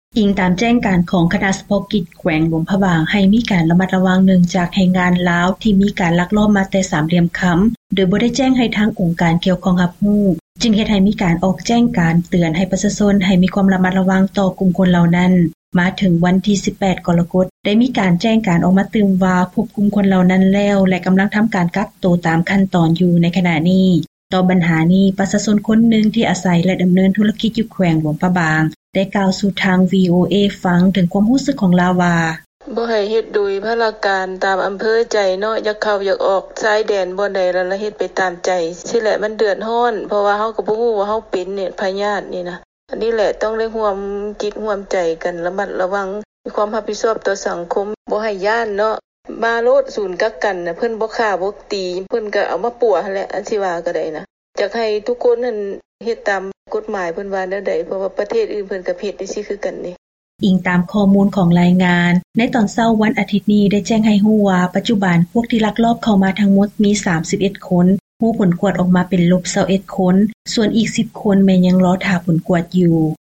ເຊີນຟັງລາຍງານ ແຮງງານລາວທີ່ລັກລອບມາແຕ່ສາມຫຼ່ຽມຄໍາ ແມ່ນພົບໂຕແລ້ວ